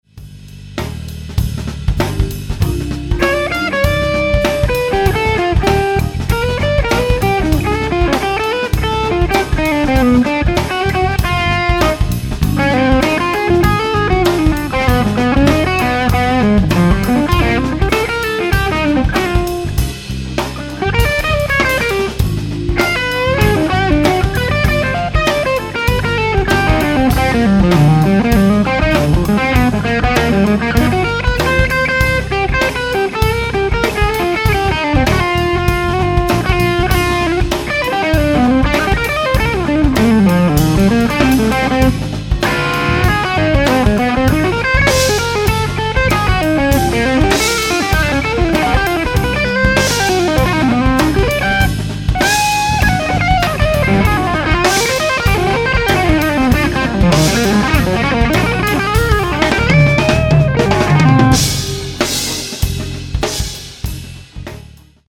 For comparison, here is 90s voice w/1265 open back and SM57.
The 90s voice take was Bluesmaster hybrid.